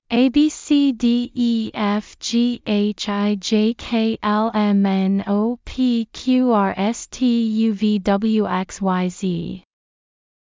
アルファベット２６文字の音声（速い）：©音読さん
alphabetfast.mp3